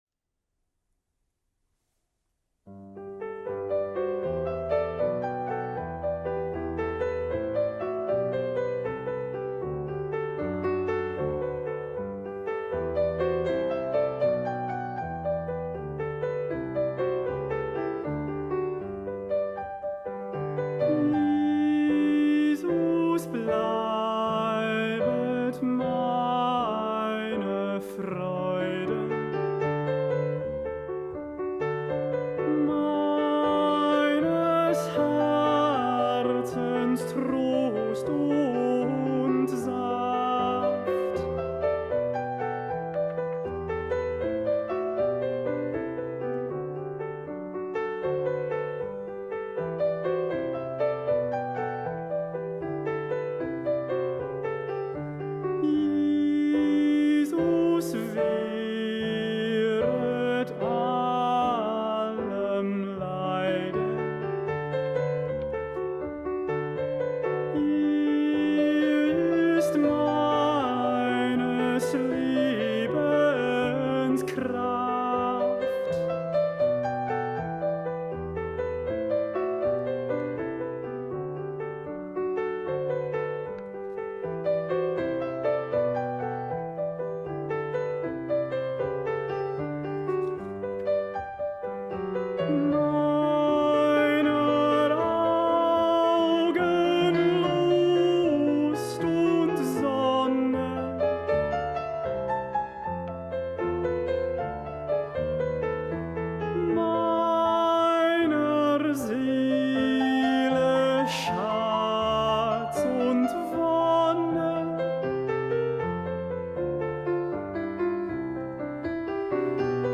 Lo ULTIMO Tenores